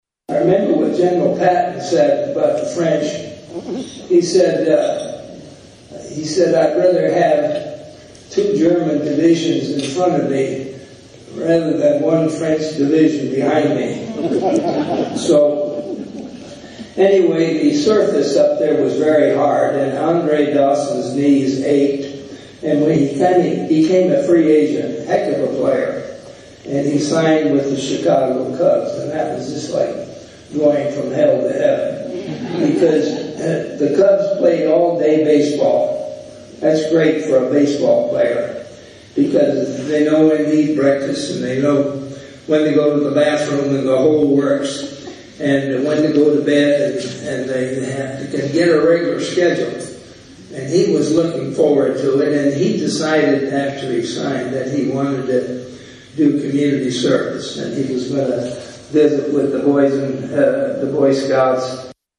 In my defense, he talked quite a bit about how the Expos were a disaster during his speech.